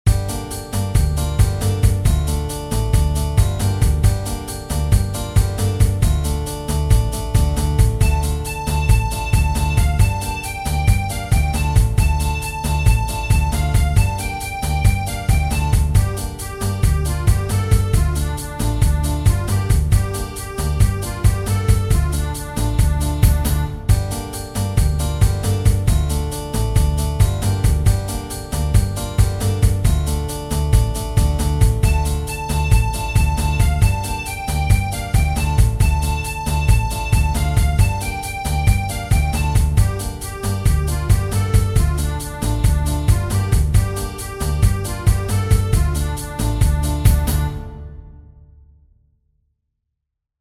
Tradizionale Genere: Folk "Svornato", noto anche come "Svornato horo" e "Gajdine svirjat", è una canzone e una danza bulgara, originaria della zona dei Monti Rodopi.